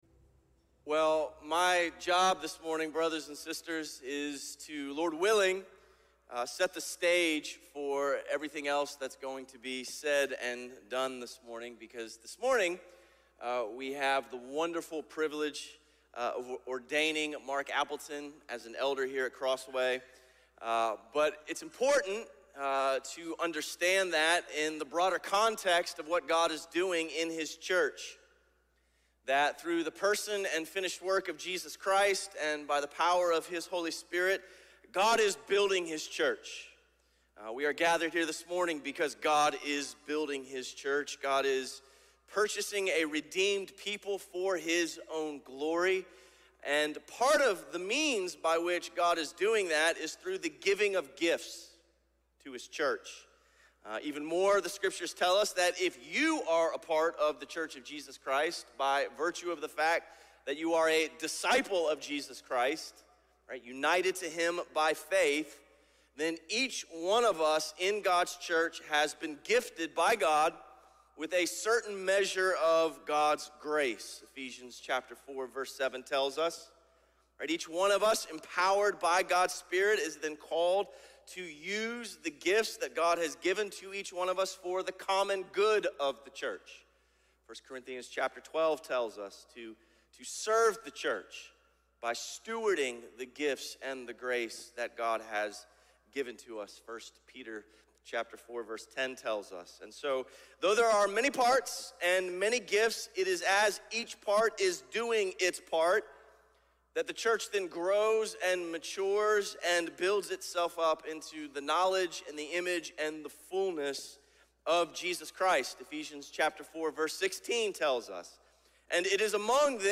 Message from Various Speakers on June 6, 2021